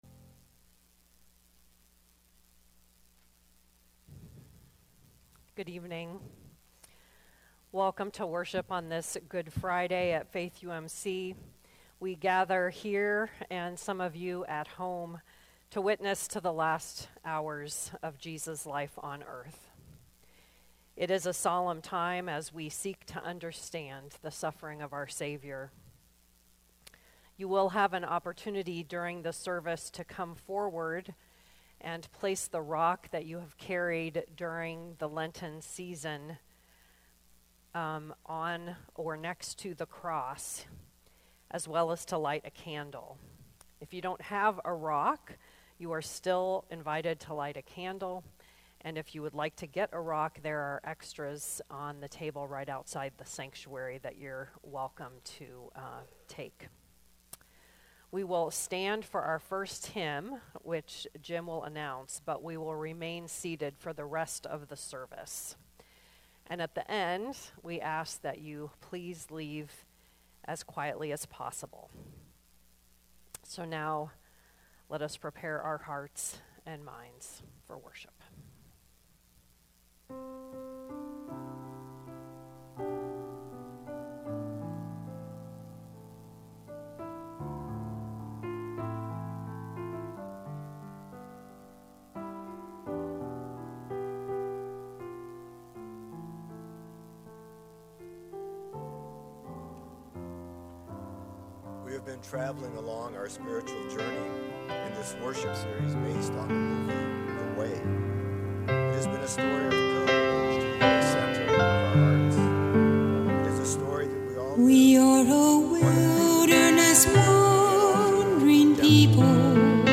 The Loving Way (Full Good Friday Service)